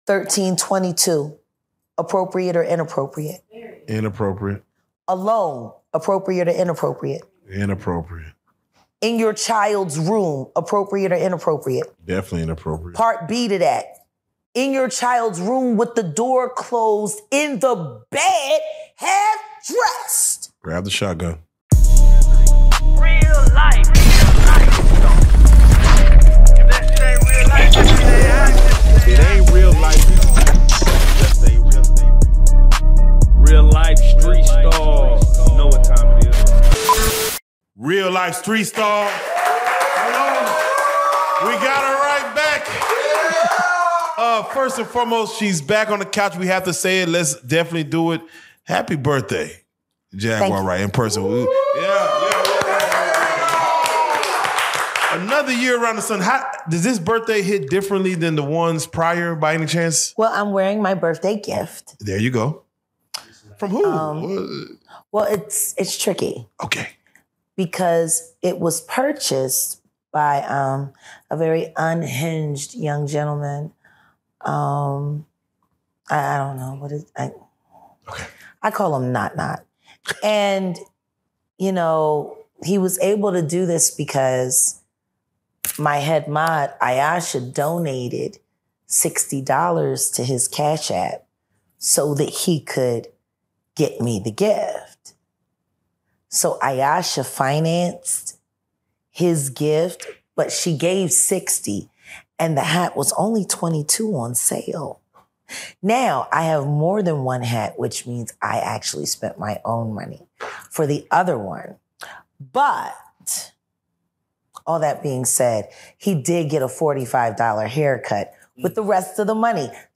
The podcast episode delves into the complexities of appropriateness in social settings, particularly focusing on the boundaries regarding children and adult interactions. Speaker A and Speaker B engage in a candid discussion that touches on personal experiences and societal norms, exploring what is deemed acceptable or inappropriate behavior, especially in the context of parenting and child safety.
The dialogue becomes increasingly intense as they draw parallels between celebrity culture and familial relationships, questioning the authenticity and motives behind public personas.